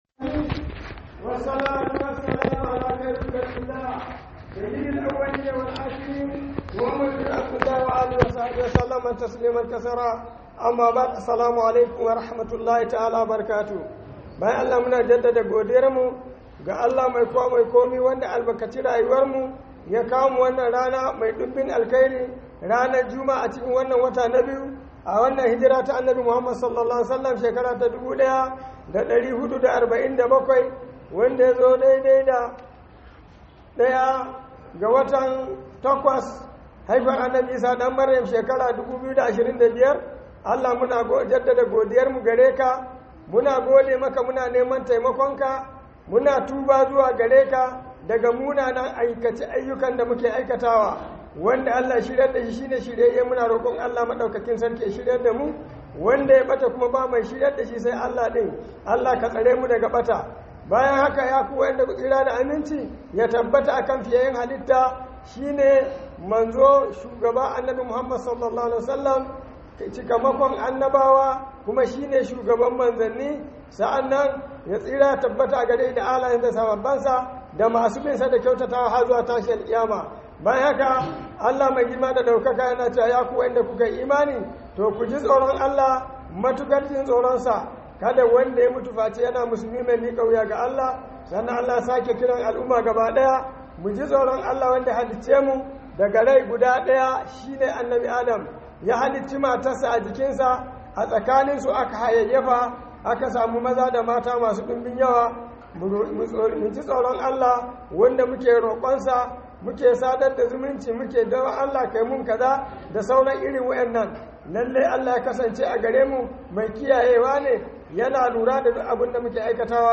Khuduba - Kadaita Allah